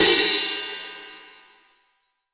Index of /90_sSampleCDs/AKAI S6000 CD-ROM - Volume 3/Hi-Hat/14INCH_FLANGE_HI_HAT